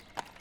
马蹄3.wav